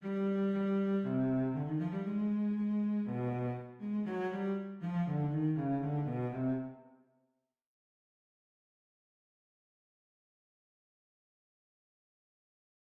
A : de caractère rythmique fortement marqué, A débute sur une quinte descendante sol do immédiatement contrecarrée par un mouvement conjoint ascendant de quatre doubles croches et suivi d’un saut de septième diminuée descendante.
C : phrase descendante basée sur les notes conjointes de la gamme la bémol, sol, fa, mi bémol, ré et do, chaque note étant précédée d’une appogiature au demi ton inférieur.
Globalement A est une phrase qui donne la part belle aux grands intervalles disjoints, alors que C est une phrase plutôt conjointe.
ex1-sujet-fugue.mp3